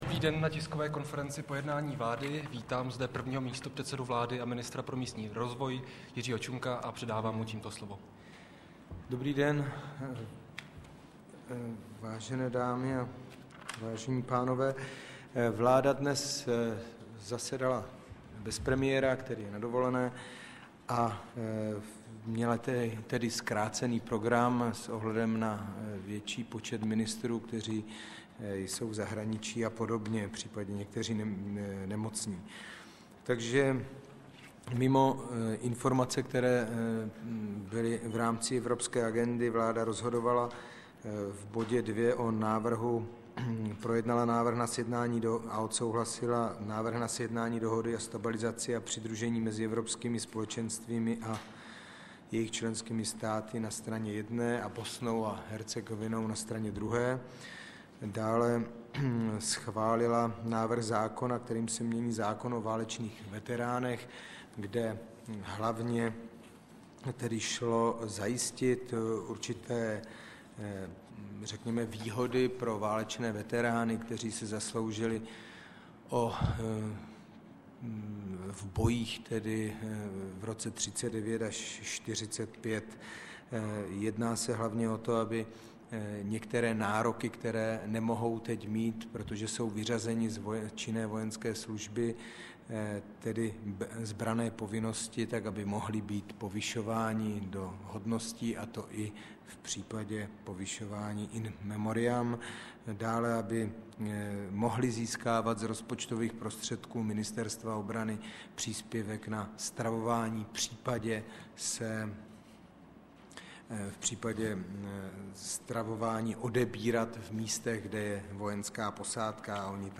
Tisková konference po jednání vlády ČR 28. května 2008